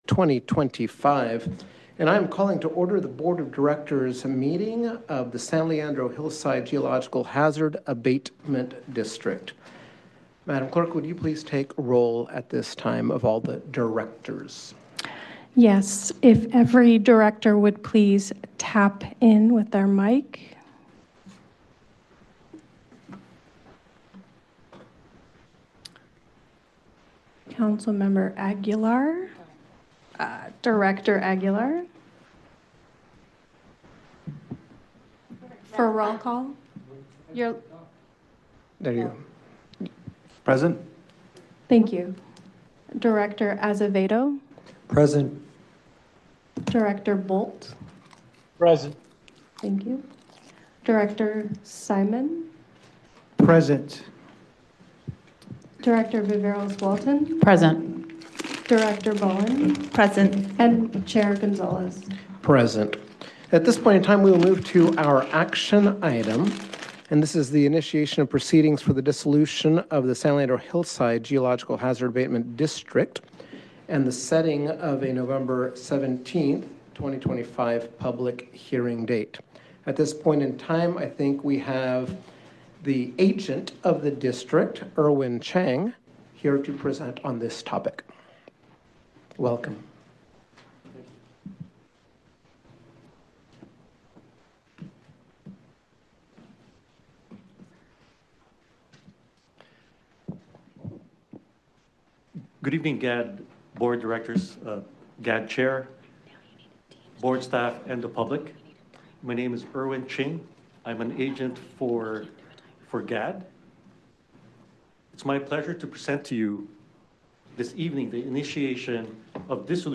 Miscellaneous Meeting on 2025-10-06 6:30 PM - Board of Directors of the San Leandro Hillside GHAD (Geologic Hazard Abatement District)SPECIAL MEETINGHosted by the City of San Leandro - Oct 06, 2025